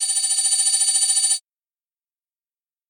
Звук города на экране с подписью в фильмах